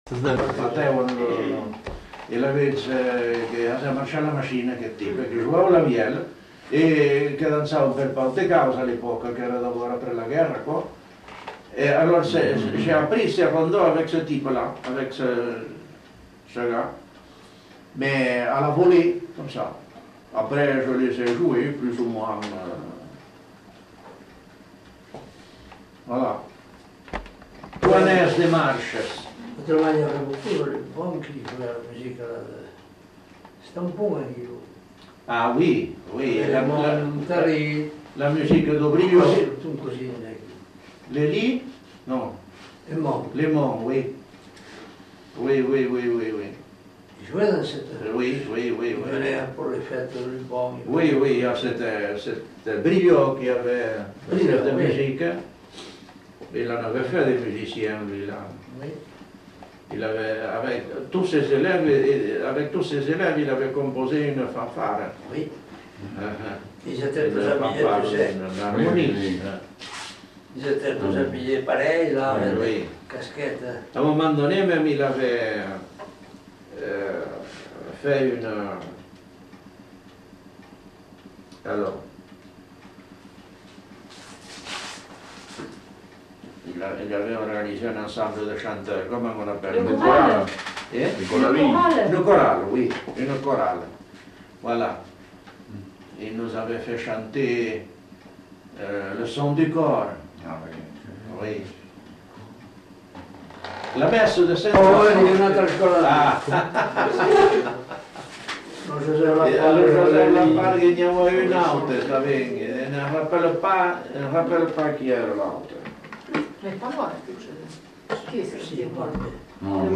Congo